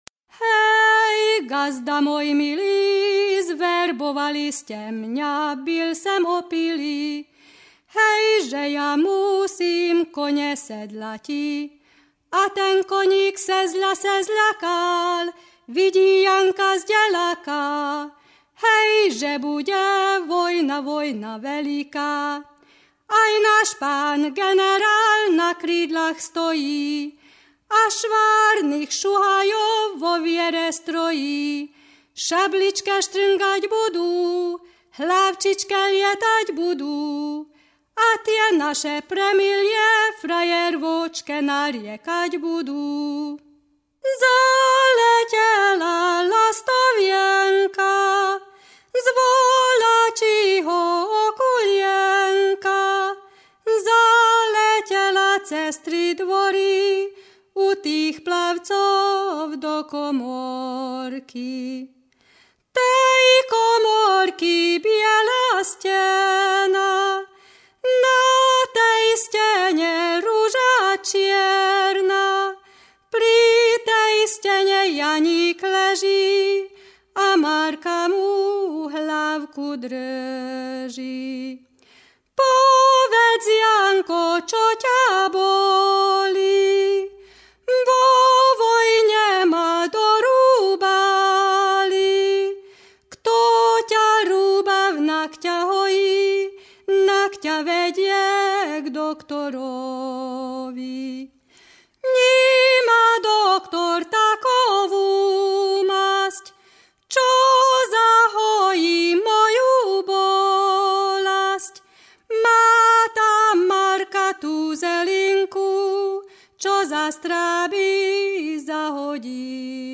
Válogatás mezőberényi népdalokból